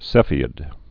(sēfē-ĭd, sĕfē-)